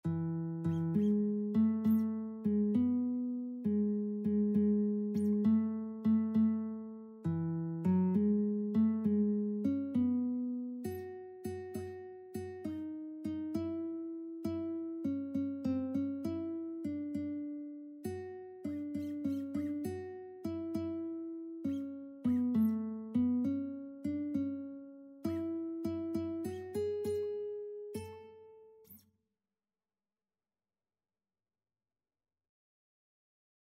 Traditional Music of unknown author.
A major (Sounding Pitch) (View more A major Music for Lead Sheets )
6/8 (View more 6/8 Music)
Christmas (View more Christmas Lead Sheets Music)